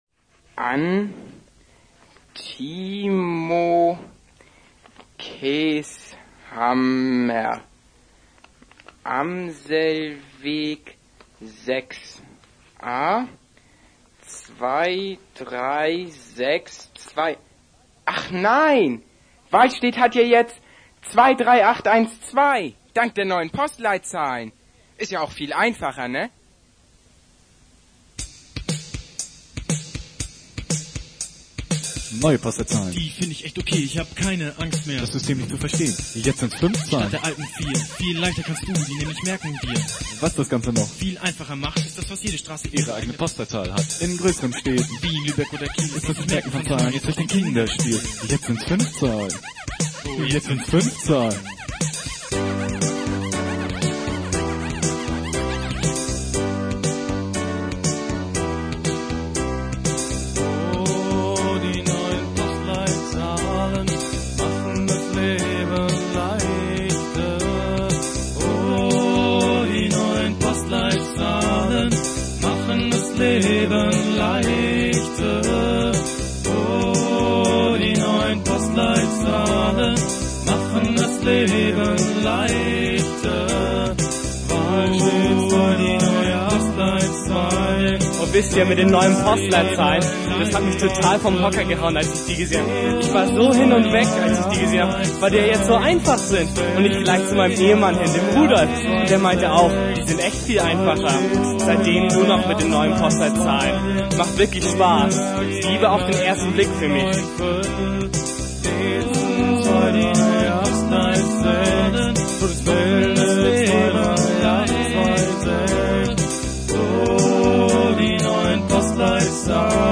Anti-folk Indie-Pop from 1992 – 1995.